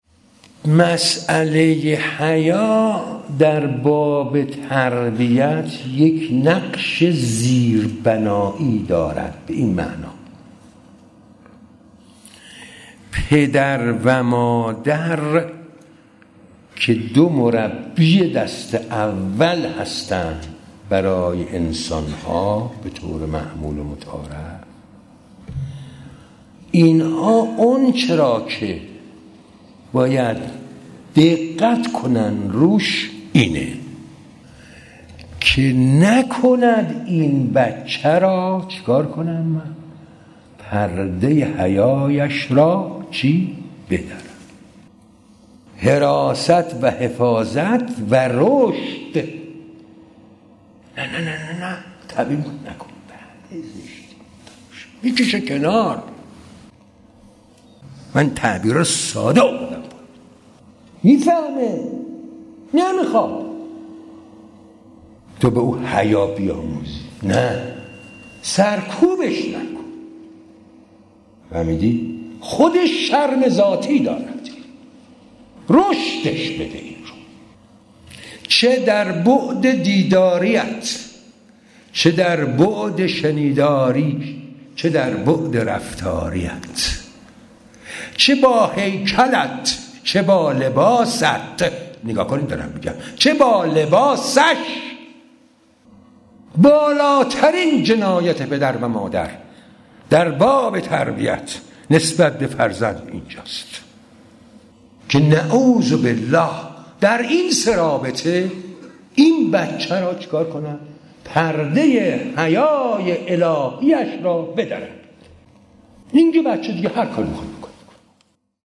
صوت/ بیانات آیت‌الله تهرانی در مساله «حیا در تربیت فرزند» - تسنیم
به گزارش خبرنگار فرهنگی باشگاه خبرنگاران تسنیم «پویا»، بیانات حضرت آیت‌الله «آقا مجتبی تهرانی» در خصوص مسأله «حیا در باب تربیت» در یک قطعه صوتی تقدیم حضورتان می‌شود.